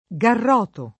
garrota [garr0ta] s. f. — strumento di supplizio: dallo sp. garrote (s. m.) — anche garrotta [garr0tta] e garotta [gar0tta] — sim. alternanza nel v. garrotare: garroto [